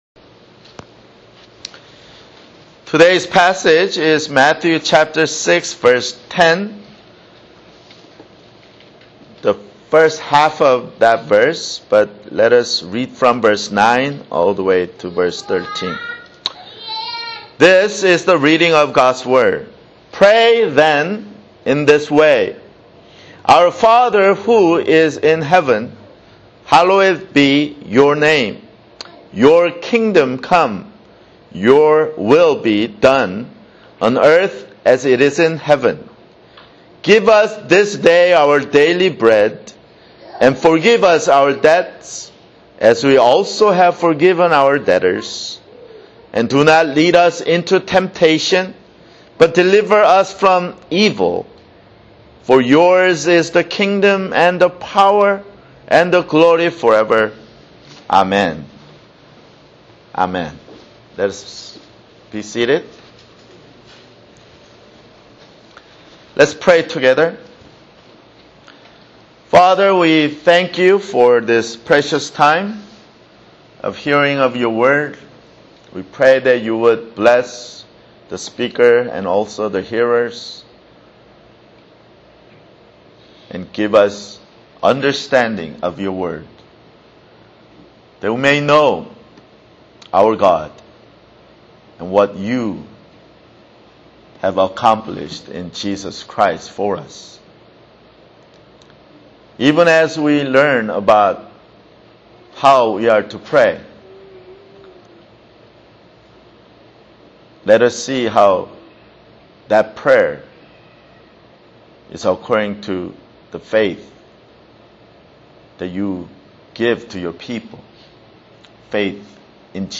[Sermon] Matthew (36)